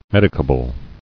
[med·i·ca·ble]